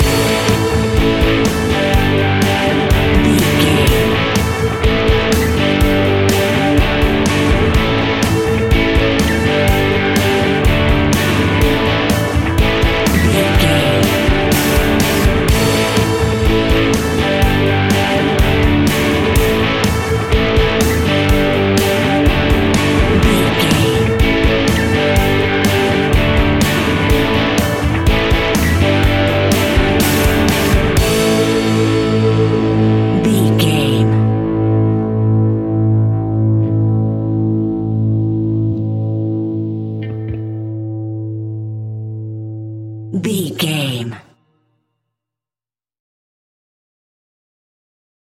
Uplifting
Mixolydian
hard rock
heavy metal
blues rock
distortion
instrumentals
Rock Bass
heavy drums
distorted guitars
hammond organ